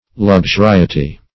luxuriety - definition of luxuriety - synonyms, pronunciation, spelling from Free Dictionary Search Result for " luxuriety" : The Collaborative International Dictionary of English v.0.48: Luxuriety \Lux`u*ri"e*ty\, n. Luxuriance.
luxuriety.mp3